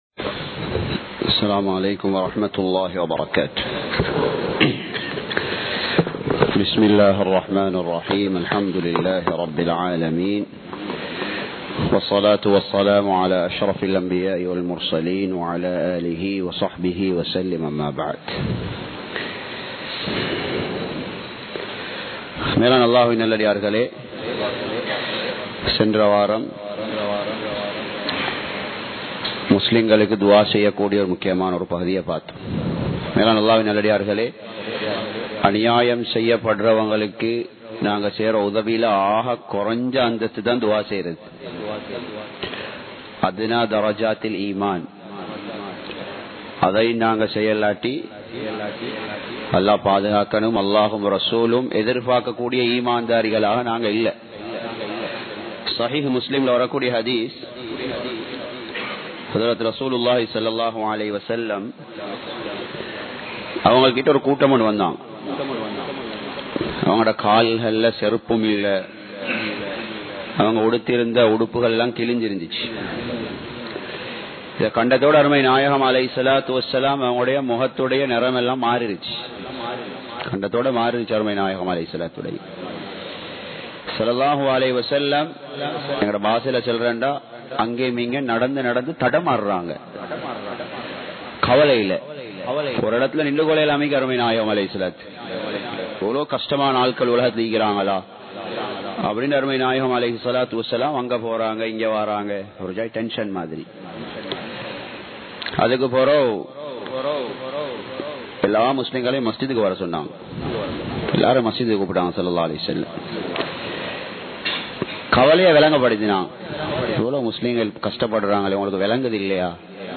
மற்றவர்களுக்கு உதவி செய்யுங்கள் (Fiqh Class) | Audio Bayans | All Ceylon Muslim Youth Community | Addalaichenai
Muhiyadeen Jumua Masjith